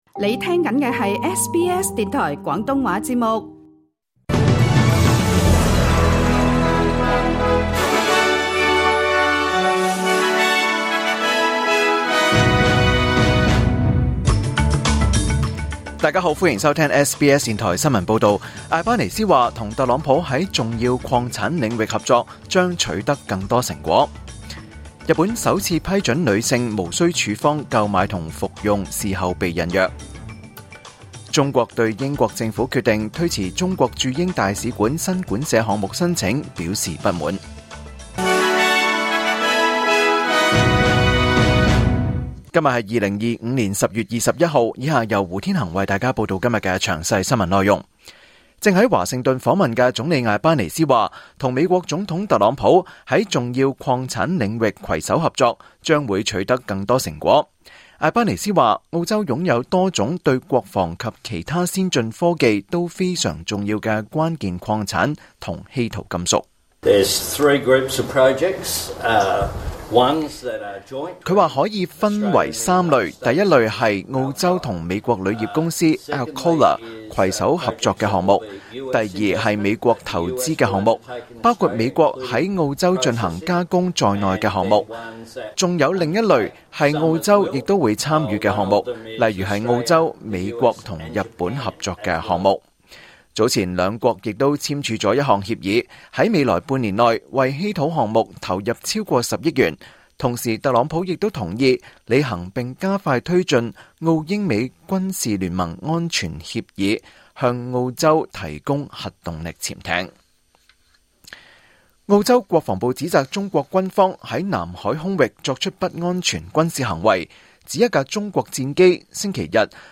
2025 年 10 月 21 日 SBS 廣東話節目詳盡早晨新聞報道。